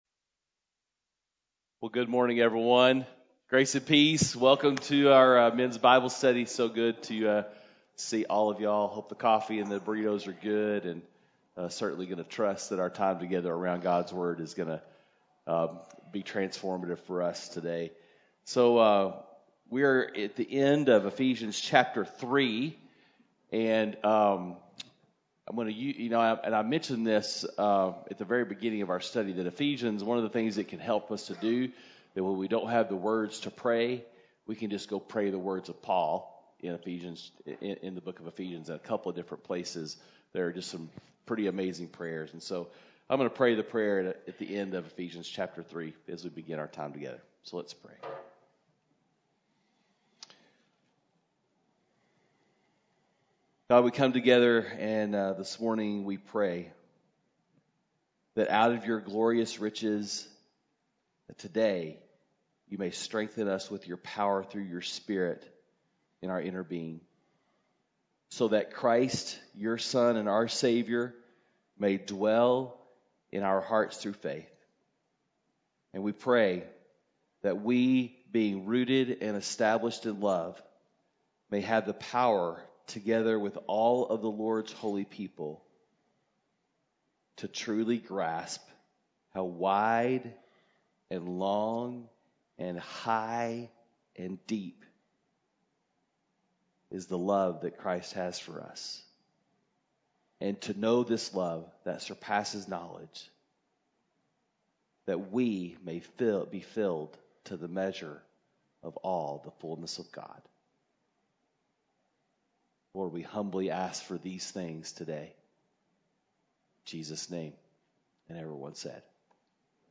Men’s Breakfast Bible Study 10/6/20